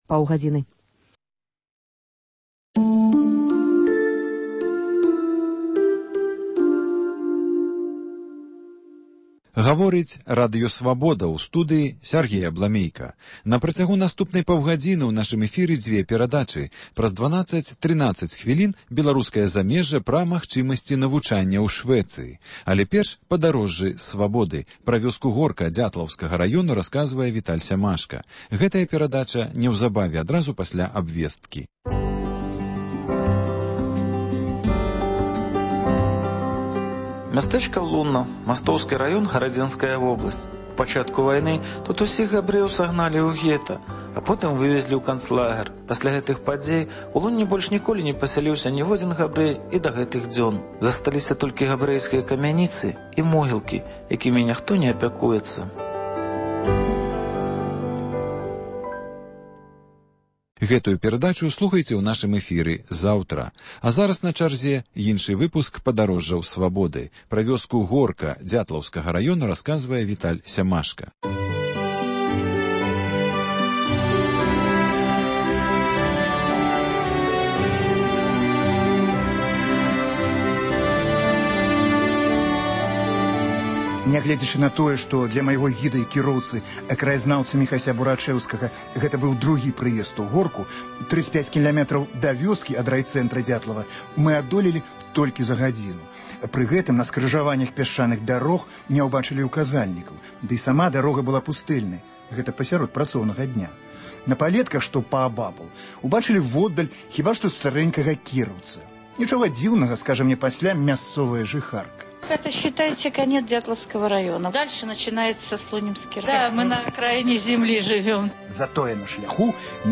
Паездкі нашых карэспандэнтаў па гарадах і вёсках Беларусі: вёска Горка Дзятлаўскага раёну.